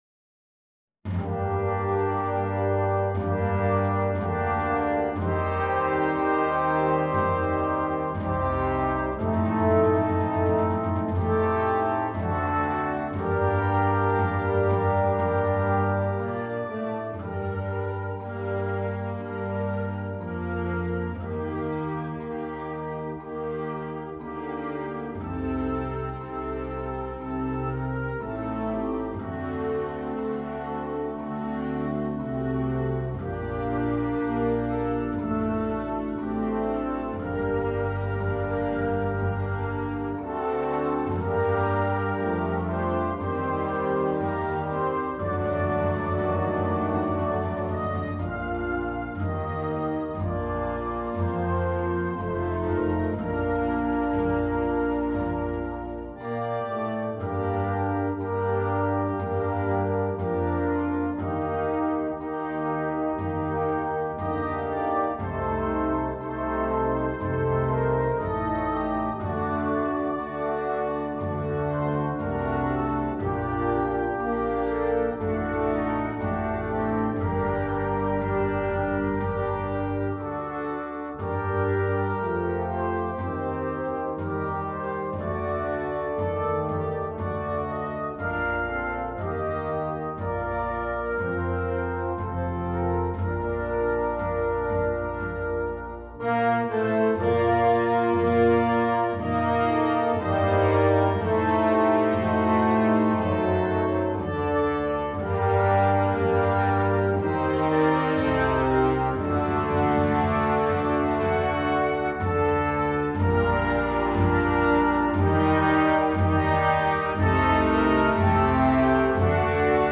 Banda completa
Himnos